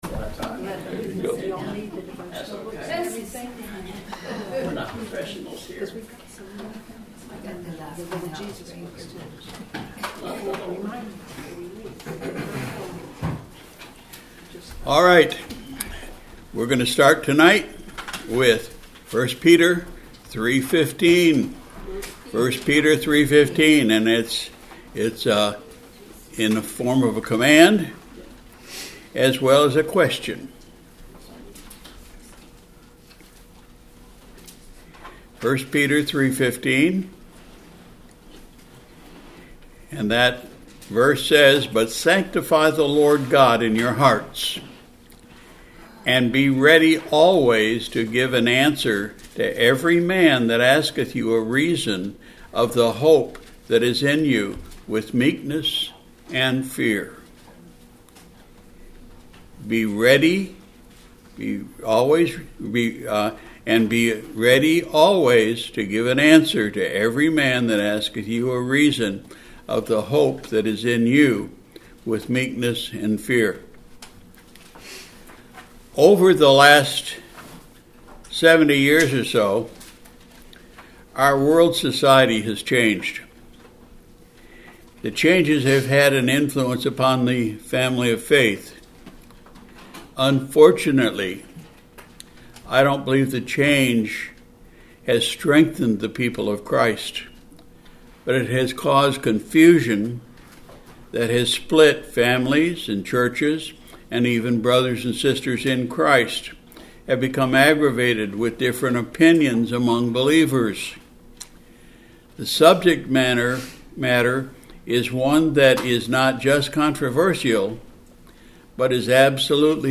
Sunday Evening Service